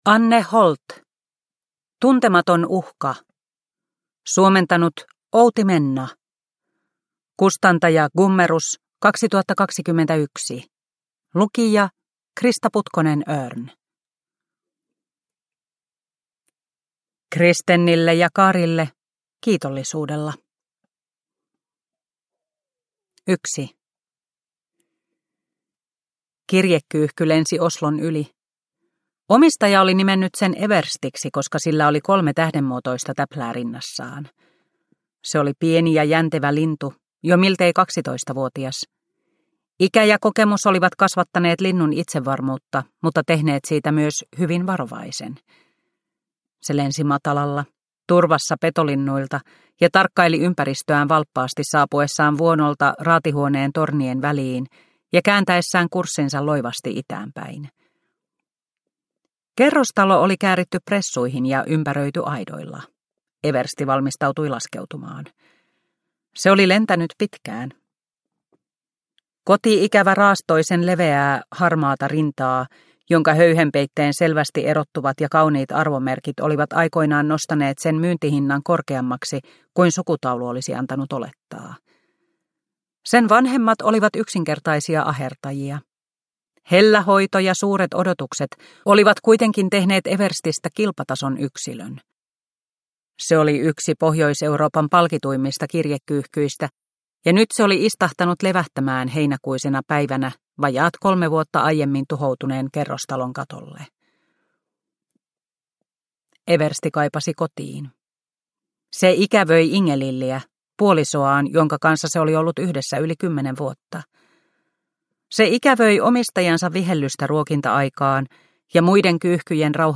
Tuntematon uhka – Ljudbok – Laddas ner